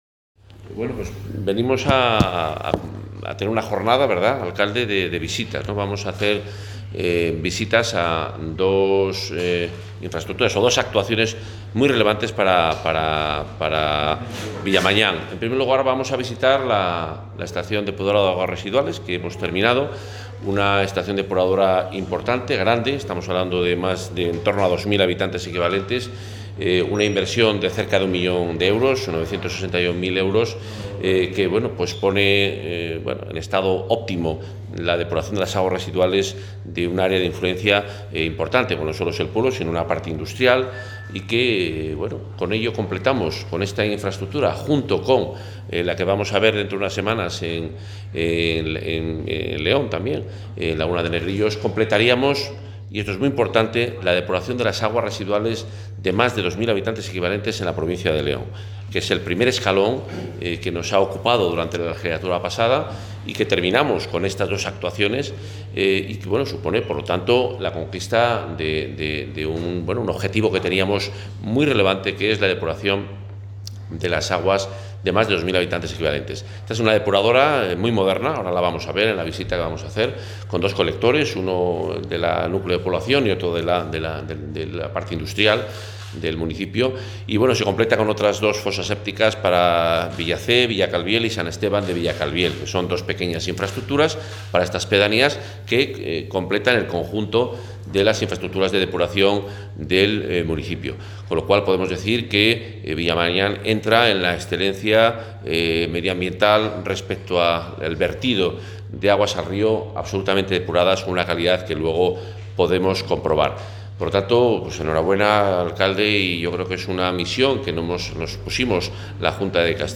Intervención del consejero.
El consejero de Fomento y Medio Ambiente ha visitado esta mañana la depuradora del municipio leonés de Villamañán, en la que la Junta ha invertido casi un millón de euros para atender a una población de 2.000 habitantes equivalentes. En este acto, Juan Carlos Suárez-Quiñones ha confirmado las previsiones de la Junta de construir más de 300 infraestructuras de depuración en la Comunidad, en municipios de entre 500 y 2.000 habitantes equivalentes, en las que se estima una inversión global de 125 millones de euros.